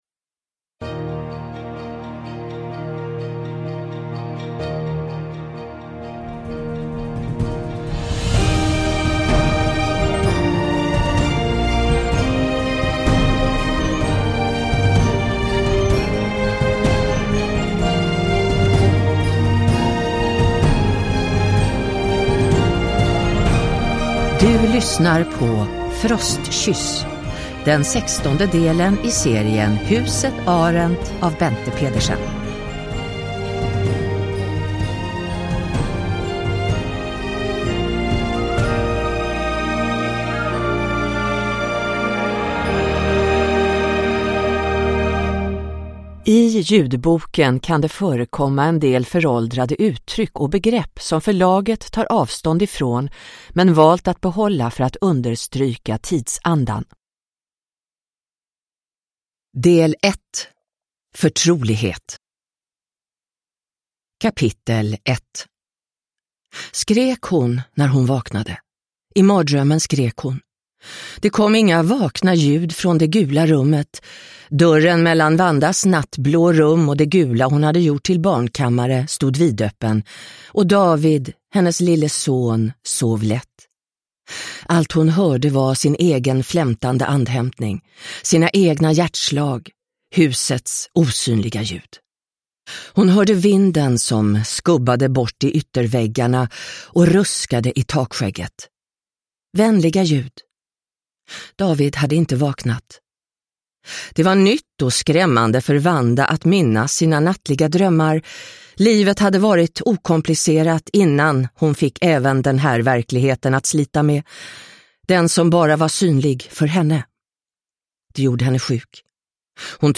Frostkyss – Ljudbok – Laddas ner